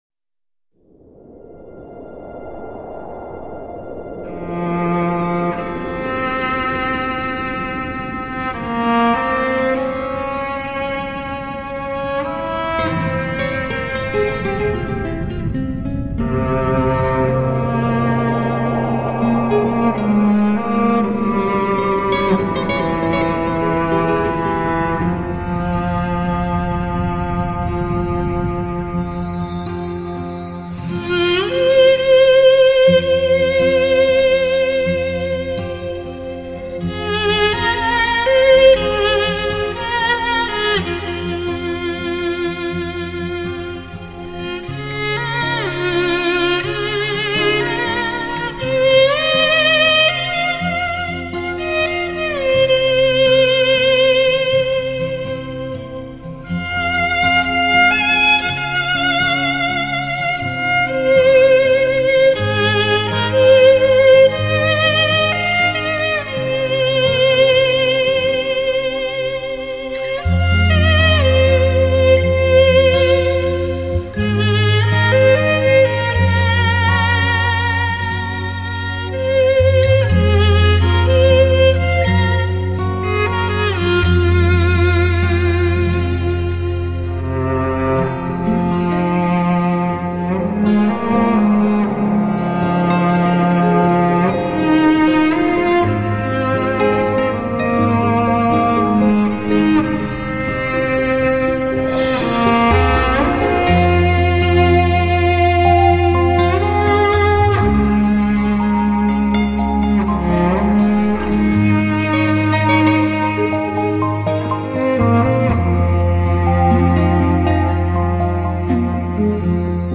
当琴声浓郁漫过原野，漫过山峦，仿佛又看到你的心在琴弦上跳动，
为低音质MP3